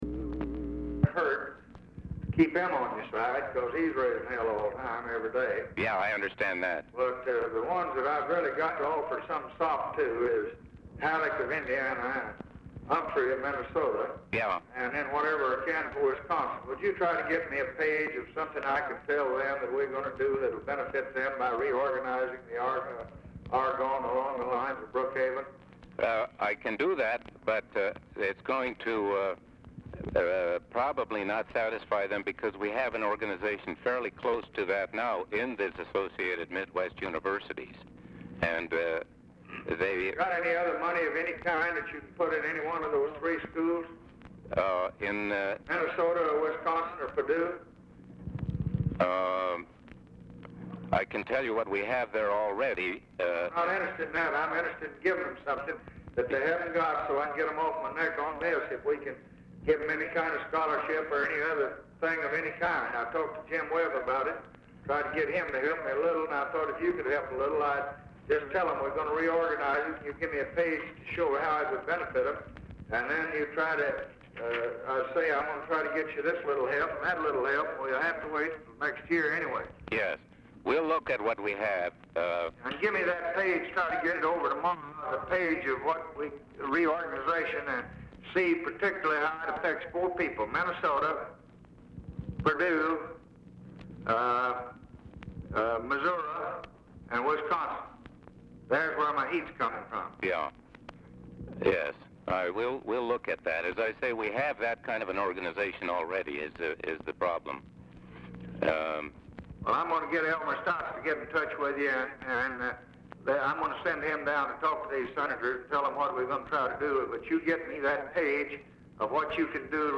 Telephone conversation # 588, sound recording, LBJ and GLENN SEABORG, 12/20/1963, 5:00PM | Discover LBJ
RECORDING STARTS AFTER CONVERSATION HAS BEGUN
Format Dictation belt
Location Of Speaker 1 Oval Office or unknown location